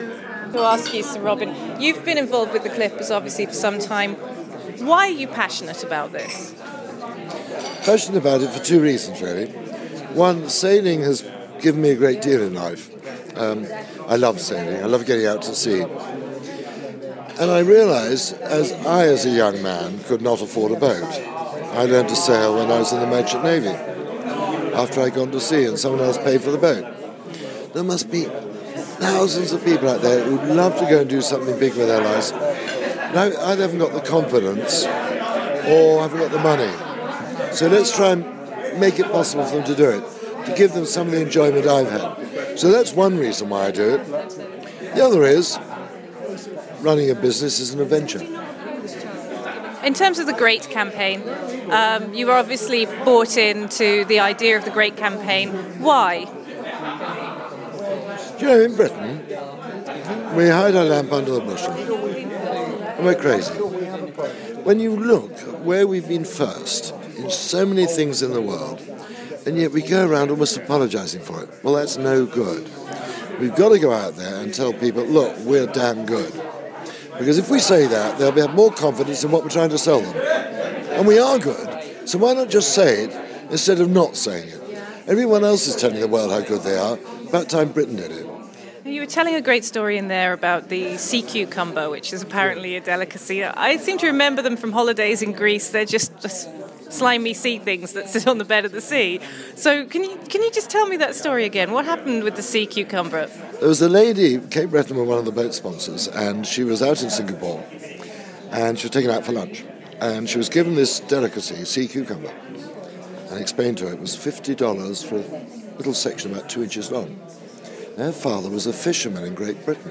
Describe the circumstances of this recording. At No 10 Downing Street.